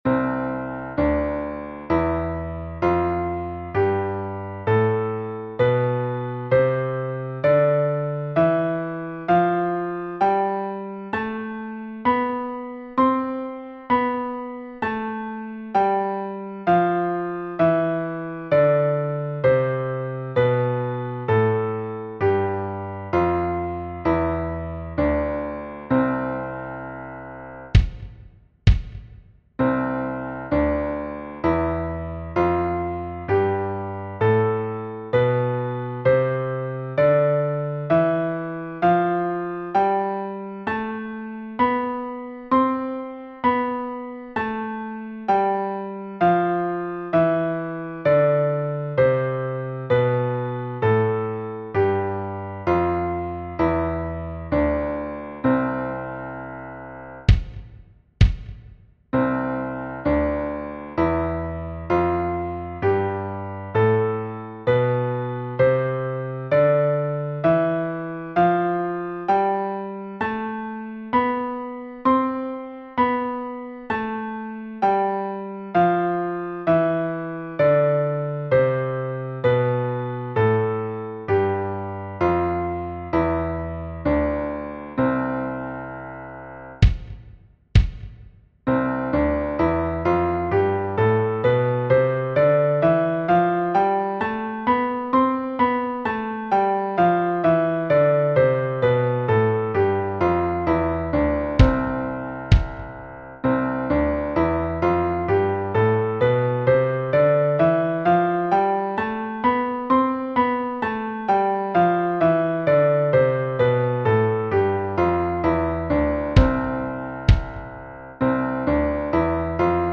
Escala-de-Do-Mayor-Partitura-MP3-de-Piano-Estudio-Negras-Corcheas-Semicorcheas.mp3